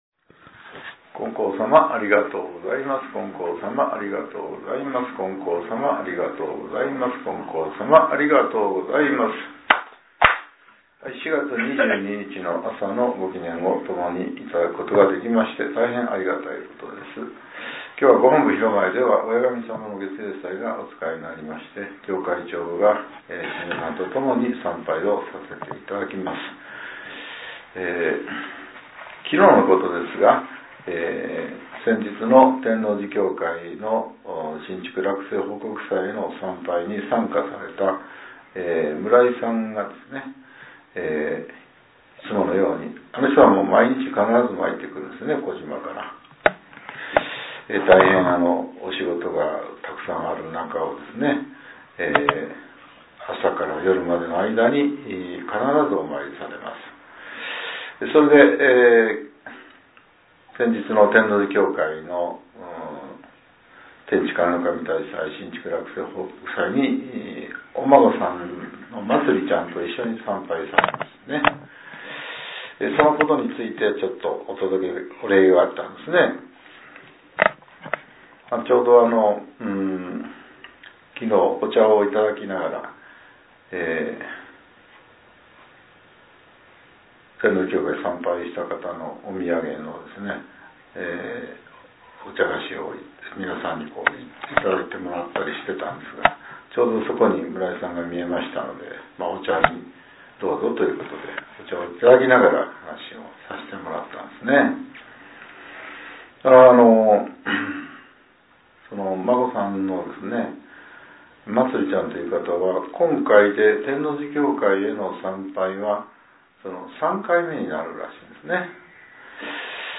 令和７年４月２２日（朝）のお話が、音声ブログとして更新されています。